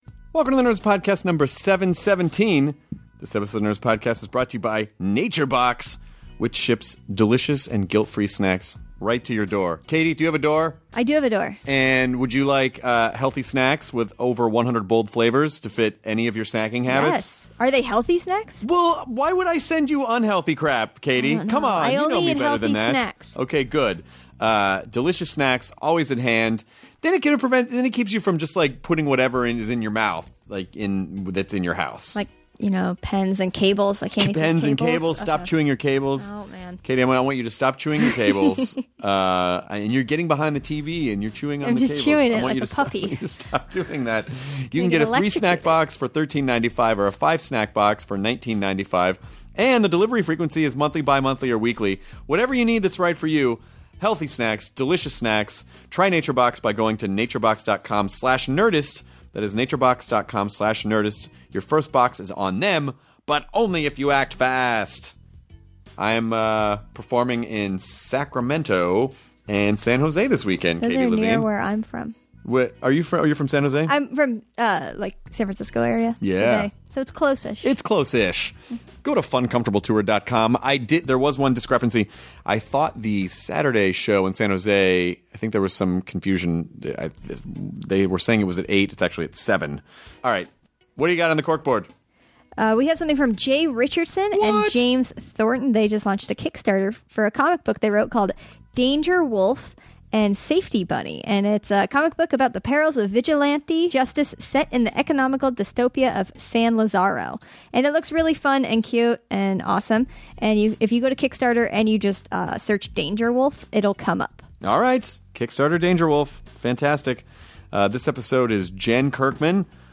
Jen Kirkman (comedian) talks with Chris about her obsession with customer service, jobs they had before they were comedians and people calling her Mrs. all the time. They also discuss the art of making small talk, being a woman in comedy and people being too easily offended these days!